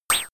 Royalty free sounds: High Frequency